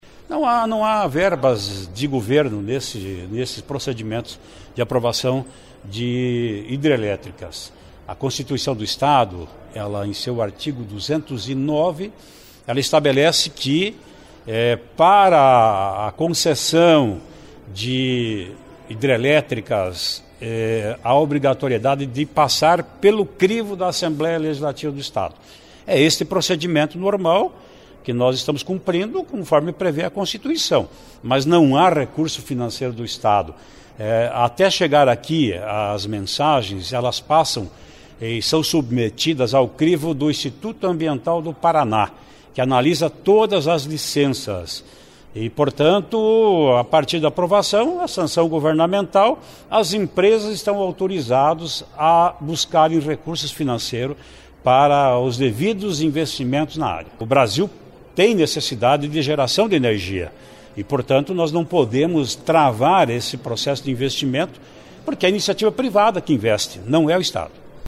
Em entrevista coletiva, presidente da Assembleia Legislativa falou do que representam as PCHS para a geração de energia e que o custo não é do Governo, mas da iniciativa privada.
(Sonora)